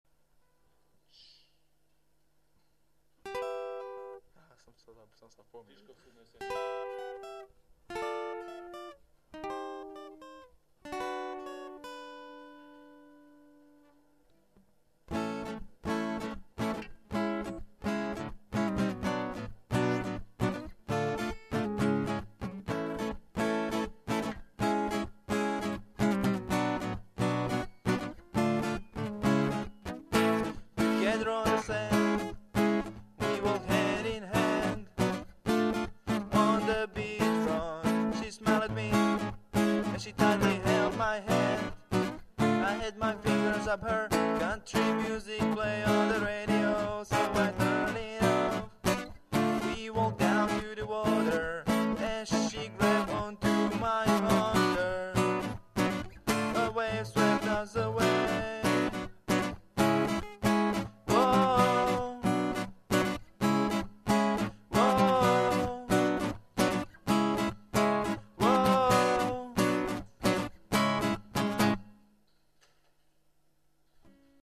Styl: Melody